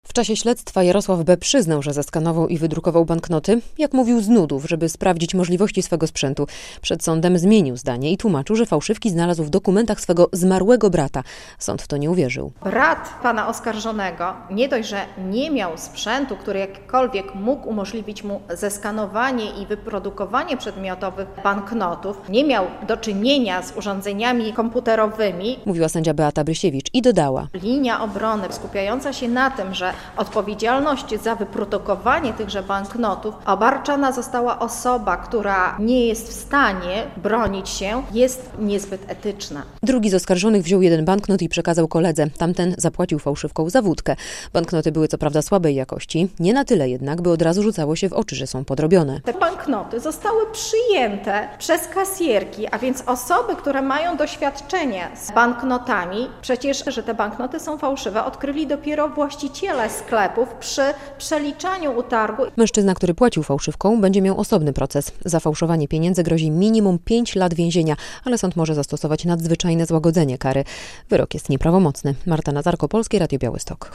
Wyrok za fałszowania pieniędzy i wprowadzanie ich do obiegu - relacja